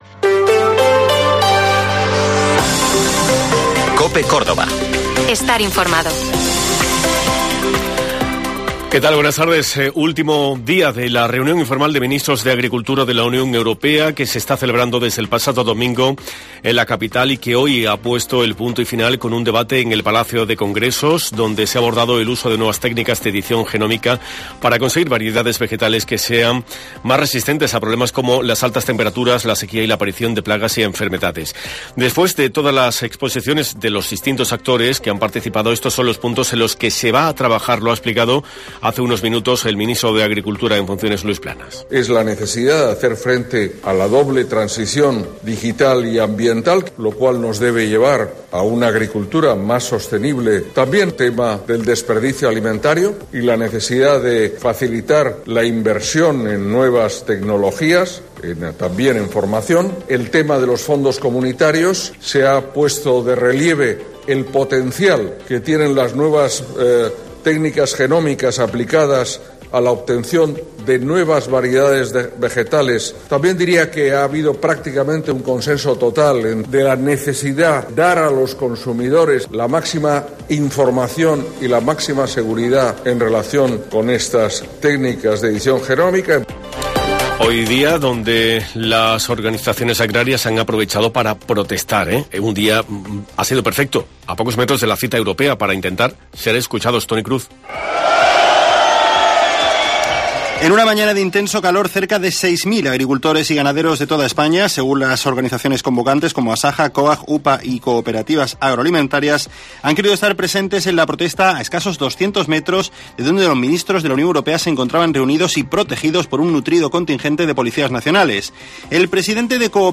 Informativo Mediodía COPE Córdoba